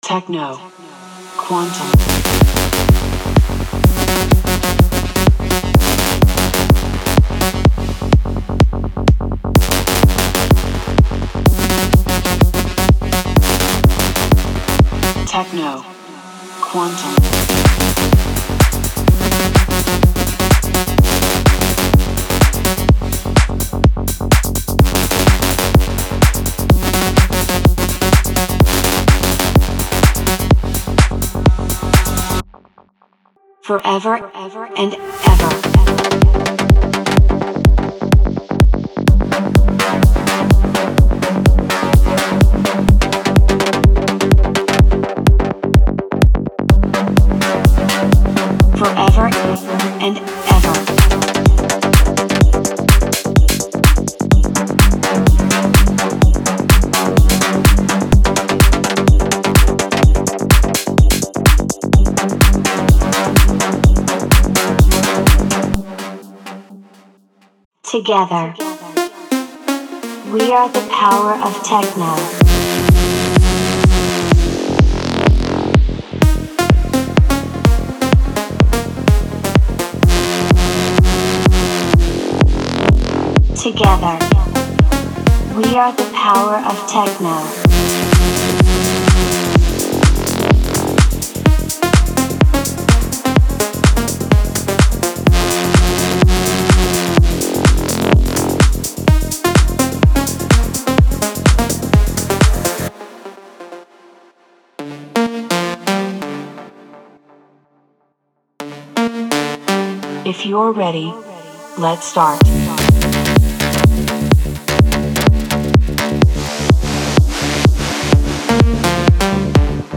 Type: Serum Samples
• Tempo Range: 126 Bpm